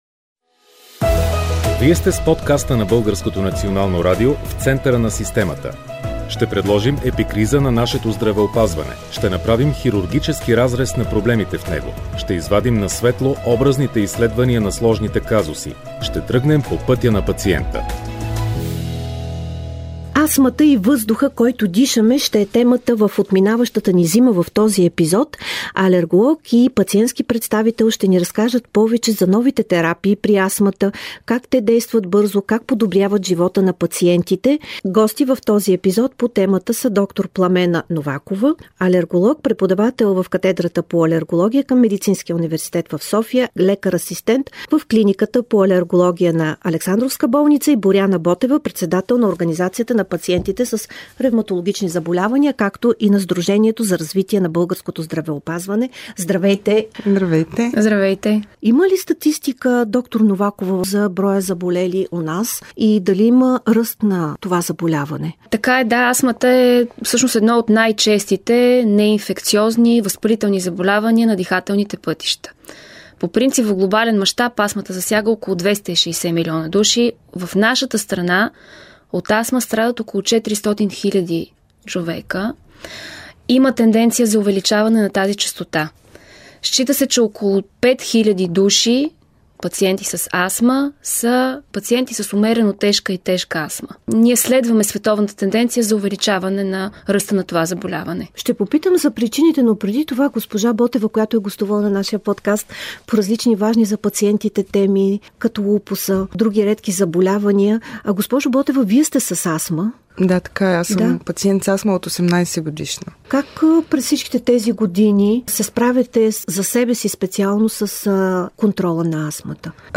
В този епизод на подкаста на БНР "В центъра на системата" алерголог и пациент разказват по темата Тежка астма